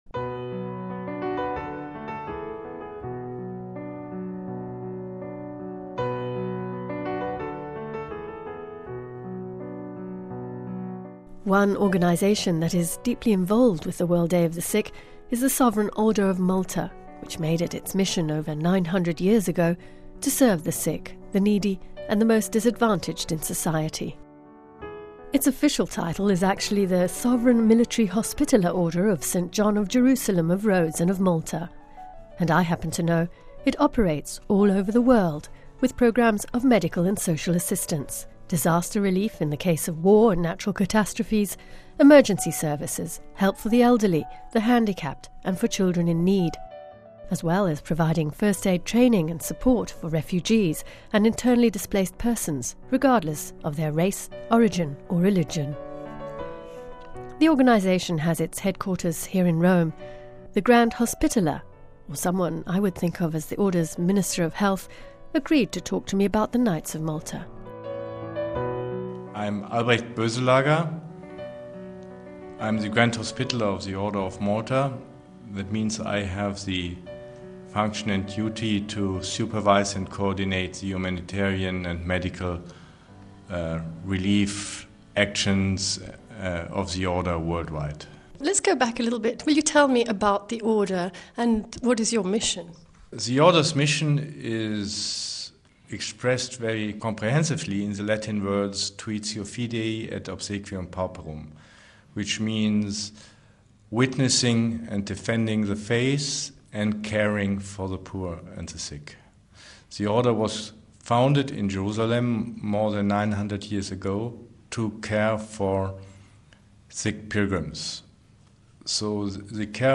Home Archivio 2009-02-09 15:09:27 SERVANTS OF THE SICK The Grand Hospitaller of the Sovreign Order of Malta talks about the Order's mission to serve the sick and the needy...